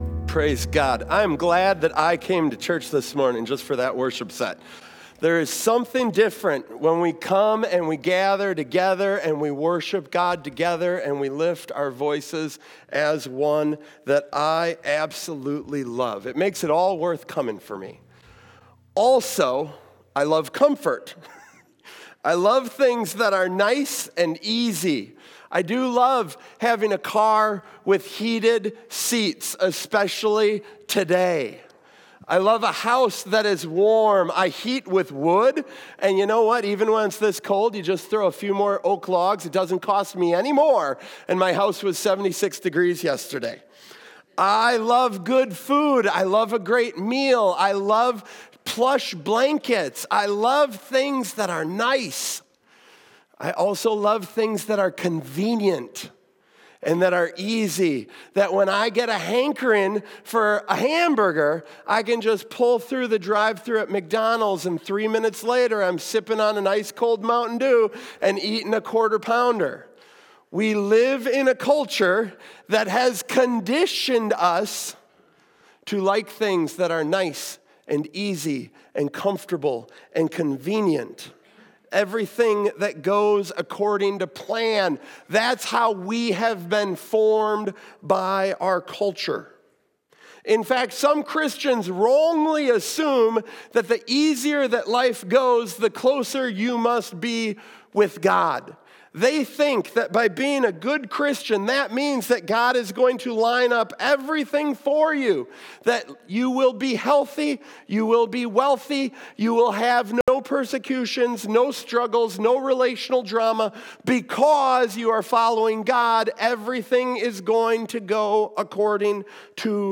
We love comfort and convenience, but what if God’s best work happens in our worst circumstances? This Christmas message takes you behind the scenes of the nativity story to reveal something we often miss: Mary’s birth experience was a total disaster by human standards.